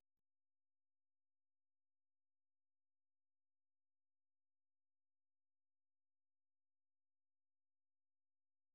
Форма симфоническая поэма
После изложения темы судьбы вводится мотив Пеллеаса, также содержащий три ноты из темы Мелизанды: